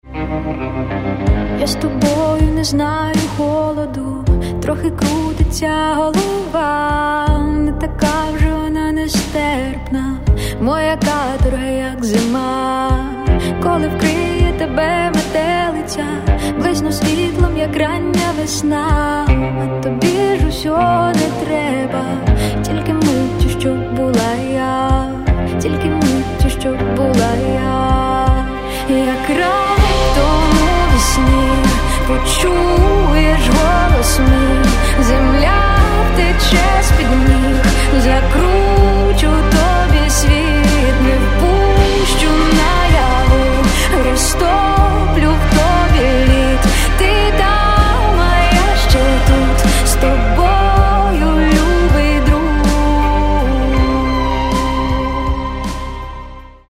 Каталог -> Поп (Легкая) -> Лирическая